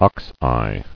[ox·eye]